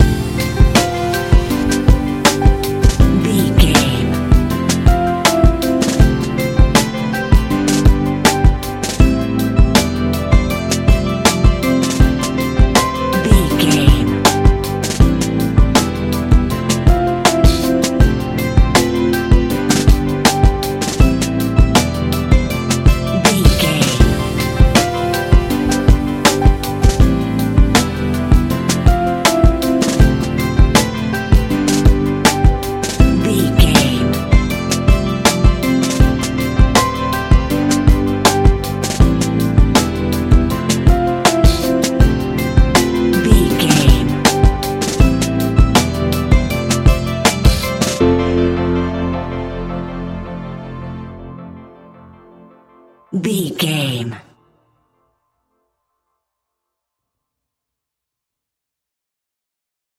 Ionian/Major
A♭
laid back
Lounge
sparse
new age
chilled electronica
ambient